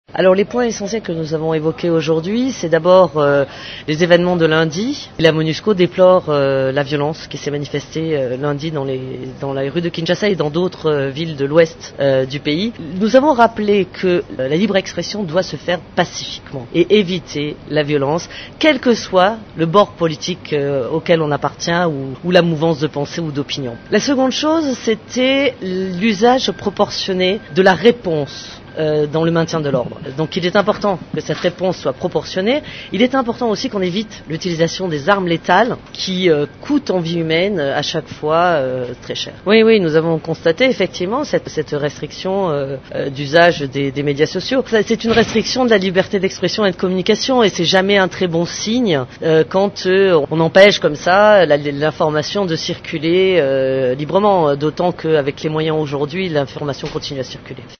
S’exprimant mercredi 9 aout en conférence de presse hebdomadaire de l’ONU